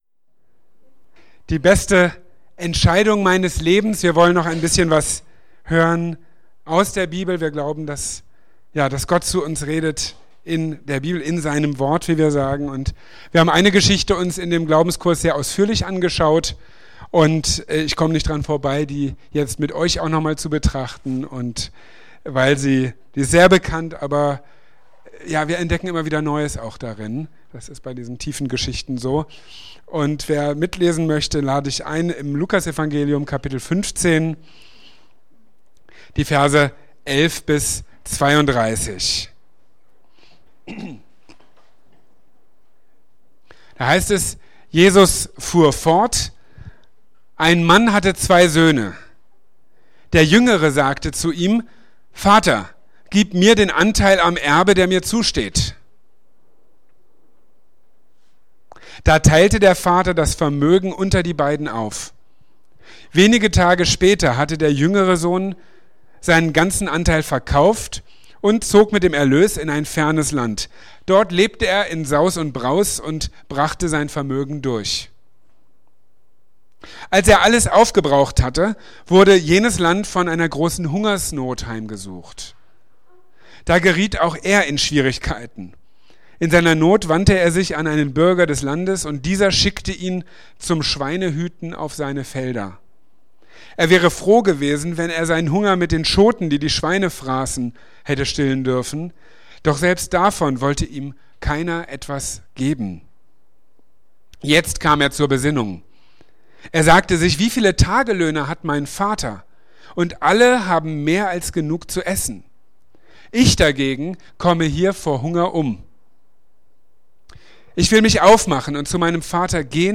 In seiner Predigt vom 22.